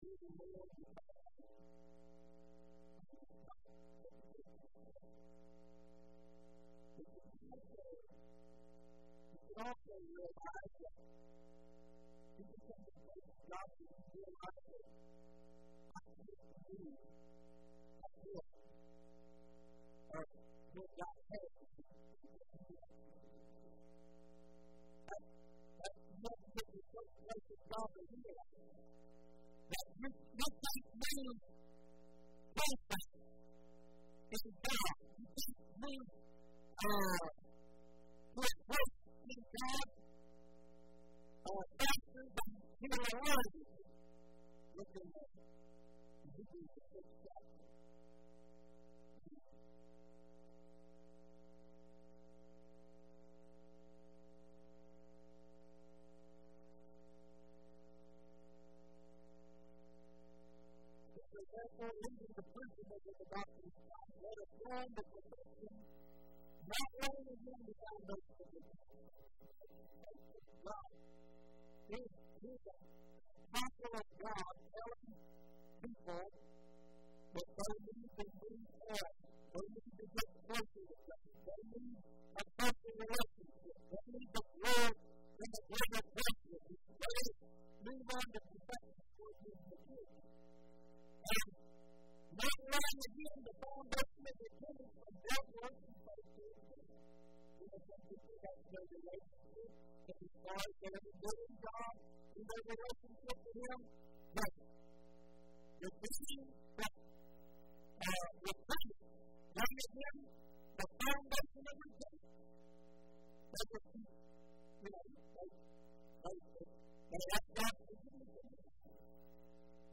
11/09/11 Wednesday Service